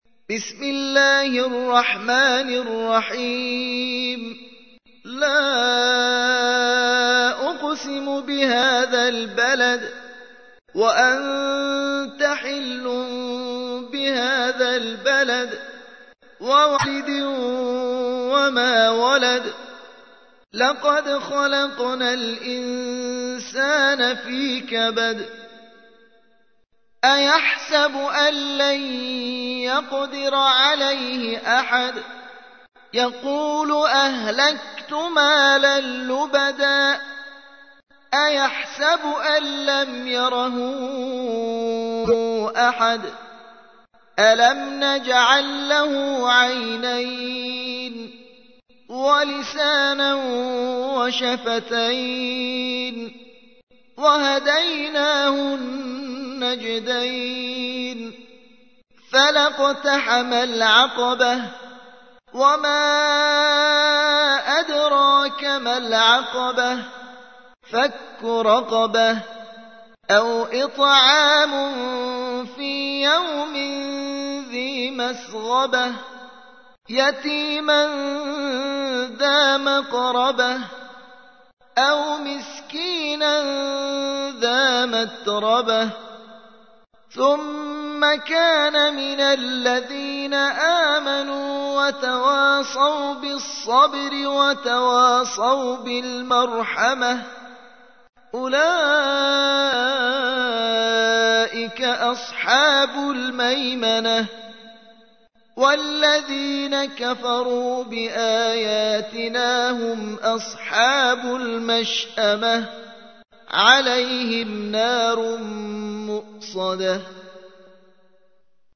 90. سورة البلد / القارئ